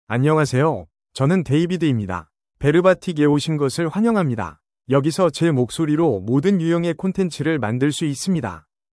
DavidMale Korean AI voice
David is a male AI voice for Korean (Korea).
Voice sample
Male
David delivers clear pronunciation with authentic Korea Korean intonation, making your content sound professionally produced.